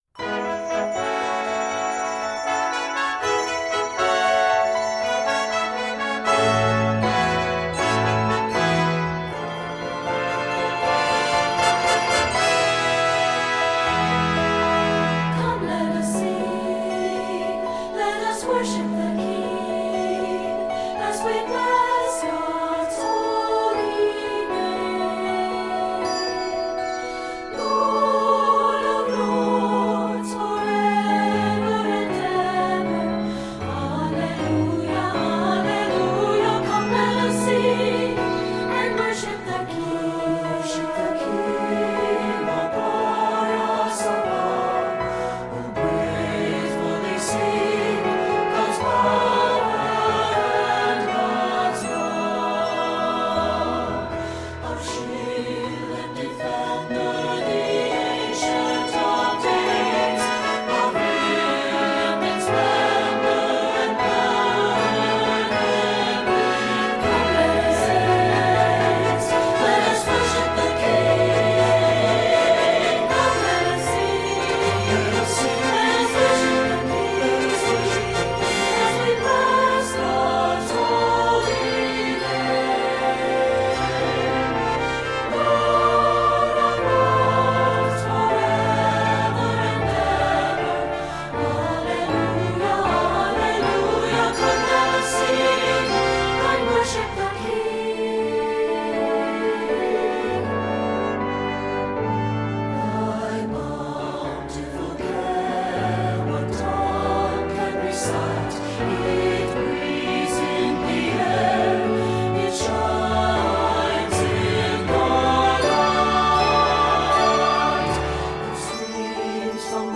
Bursting with joyous praise